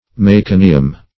Meconium \Me*co"ni*um\, n. [L., fr. Gr.